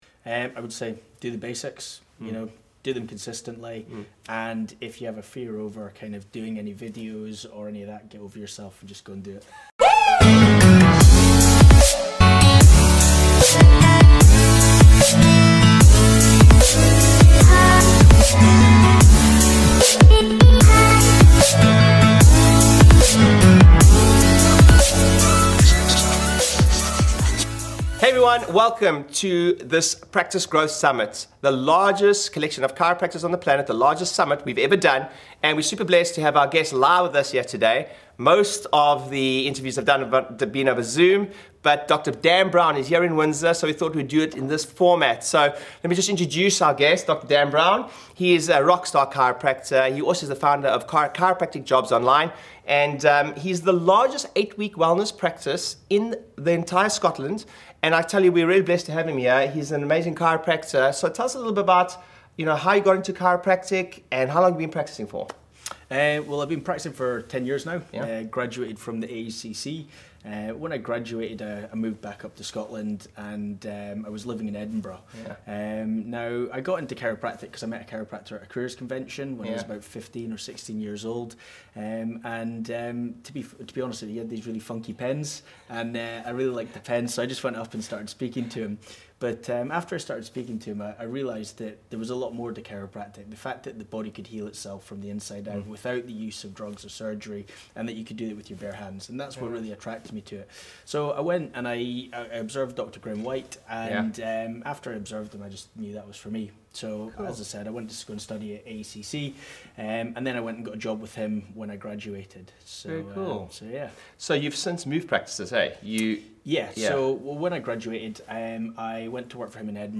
The interview took a main focus on being omni present when it comes to market, the power of being everywhere really does work!